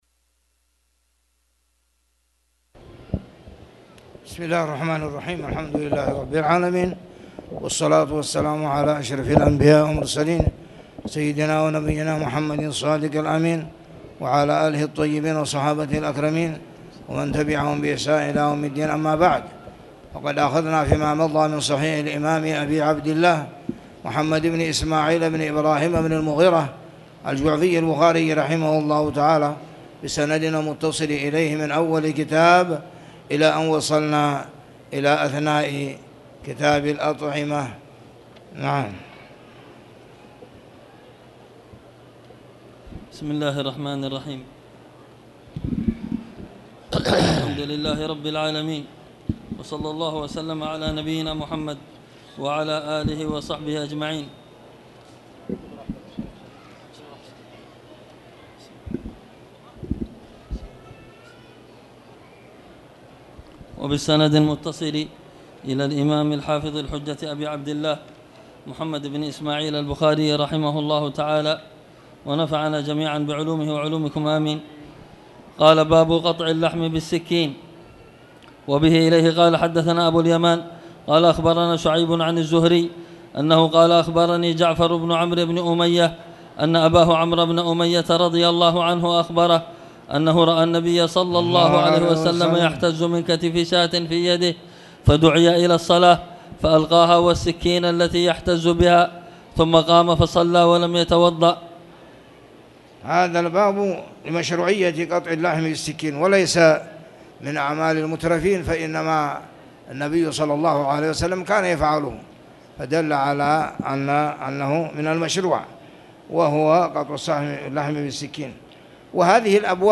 تاريخ النشر ٢٩ محرم ١٤٣٨ هـ المكان: المسجد الحرام الشيخ